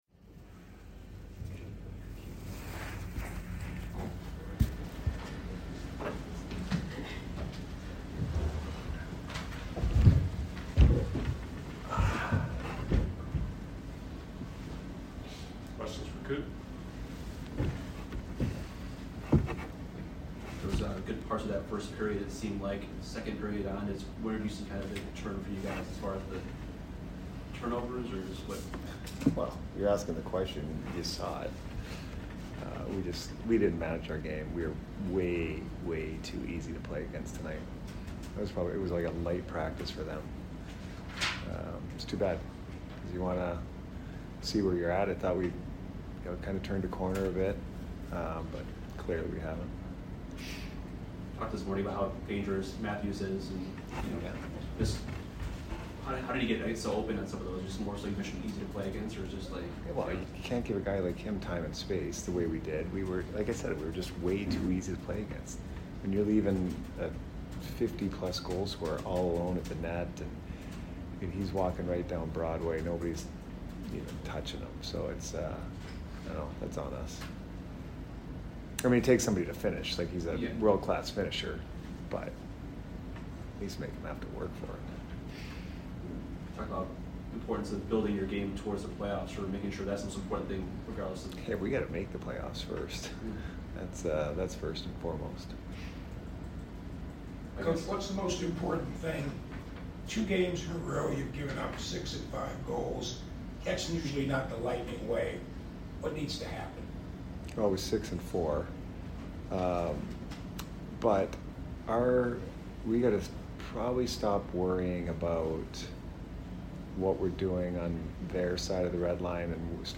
Head Coach Jon Cooper Post Game Vs TOR 4 - 4-2022